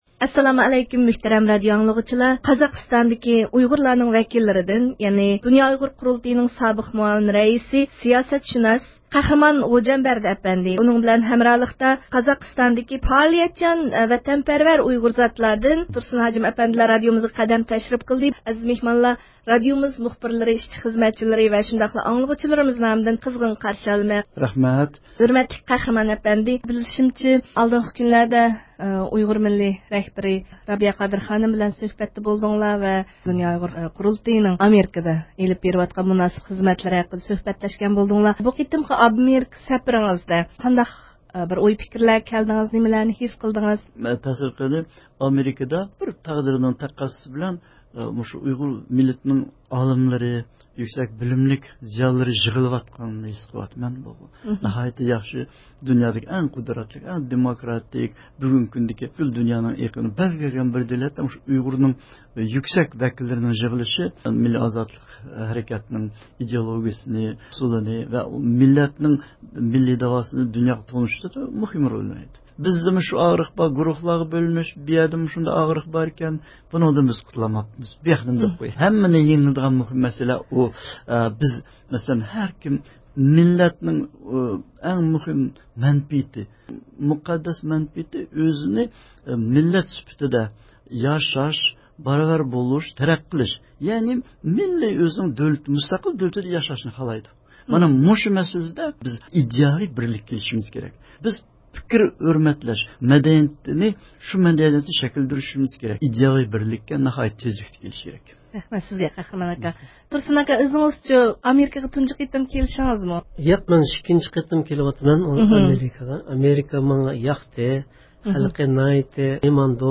يۇقىرىدىكى ئاۋاز ئۇلىنىشىدىن، ئىستانسىمىزدا بۇ قەدىرلىك مېھمانلار بىلەن مۇخبىرىمىزنىڭ ئۆتكۈزگەن سۆھبىتىگە بىرلىكتە ئىشتىراك قىلايلى.